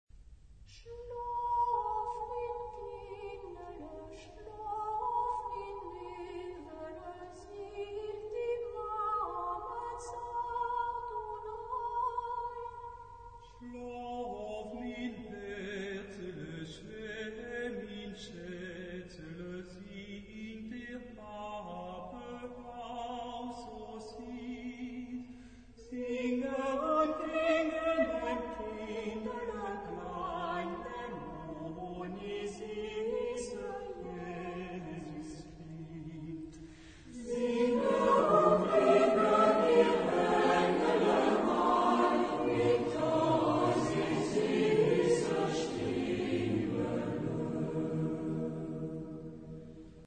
Género/Estilo/Forma: Popular ; Profano ; Canción de Navidad
Carácter de la pieza : pastoral ; alegre
Tipo de formación coral: SATB  (4 voces Coro mixto )
Solistas : Soprano (1) / Ténor (1)  (2 solista(s) )
Tonalidad : mi bemol mayor